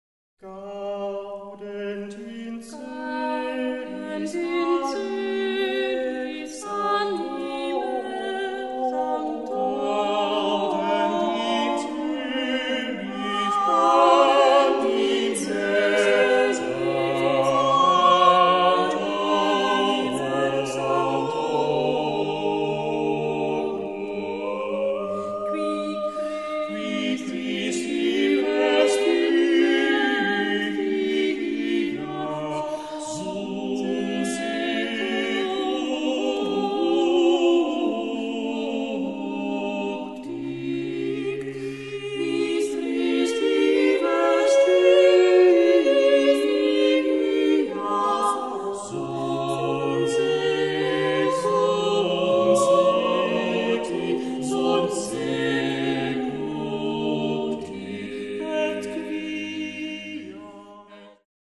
Vokalmusik